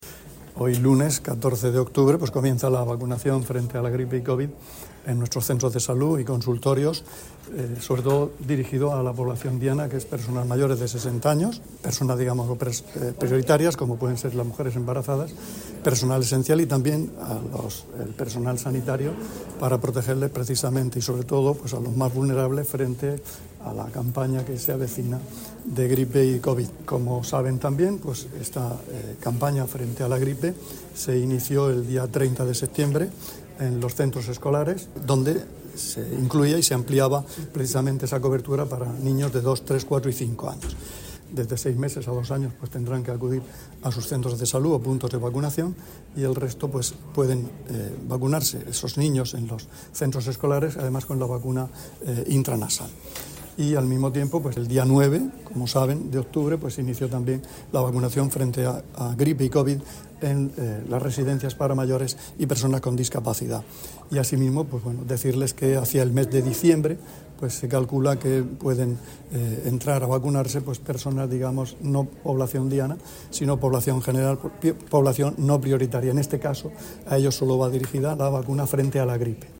Declaraciones del consejero de Salud, Juan José Pedreño, con motivo del inicio de la vacunación frente a la gripe a población mayor de 60 años y personas con factores de riesgo [MP3]